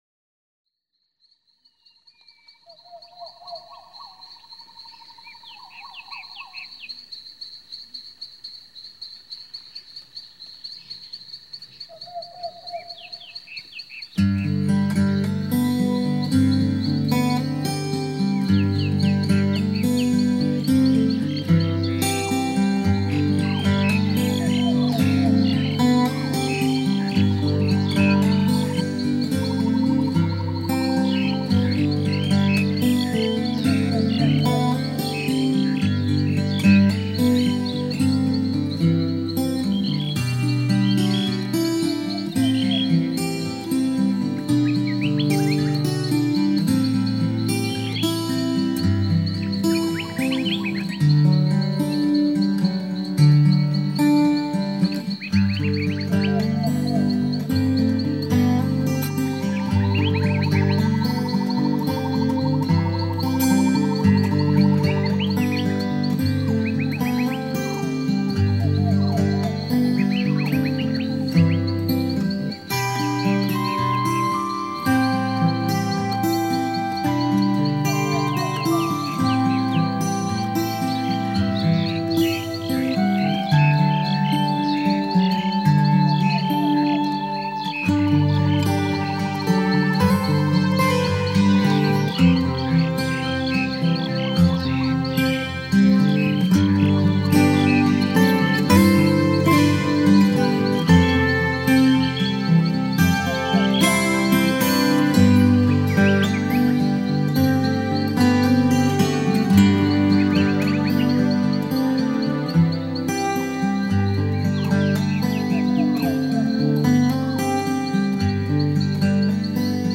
帶您進入一個大自然與器 槳交織出的無限世界．